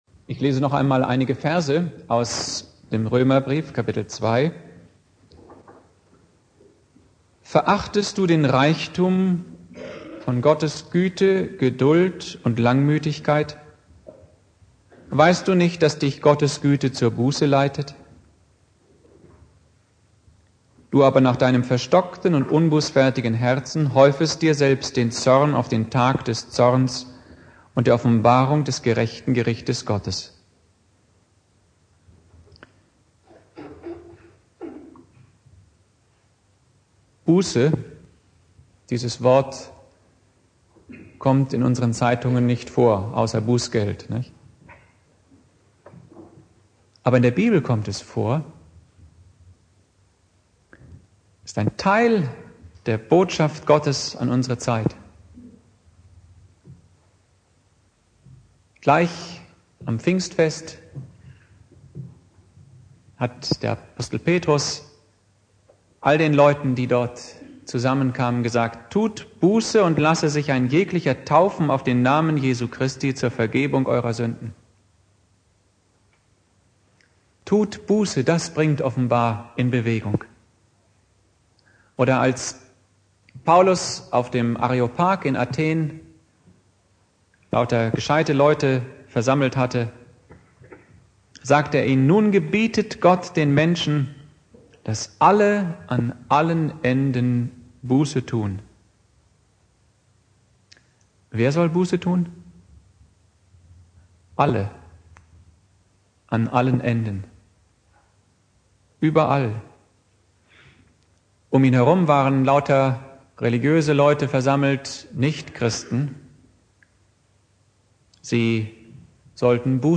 Predigt
Buß- und Bettag